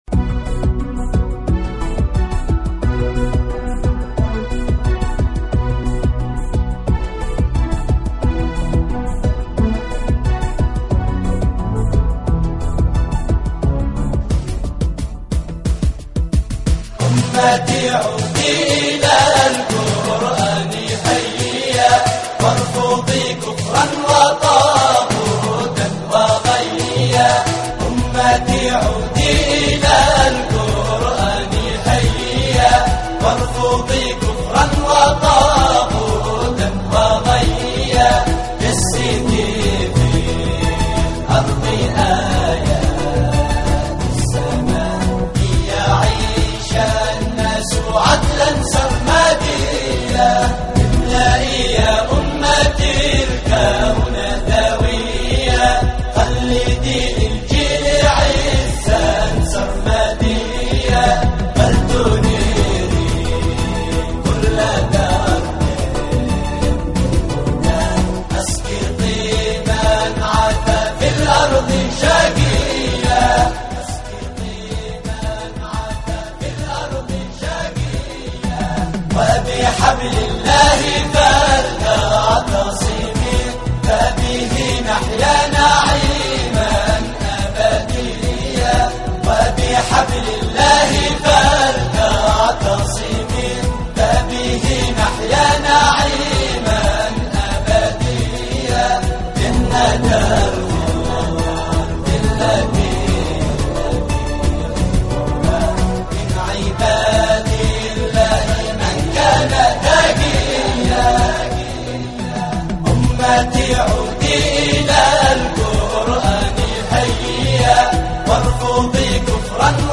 اناشيد يمنية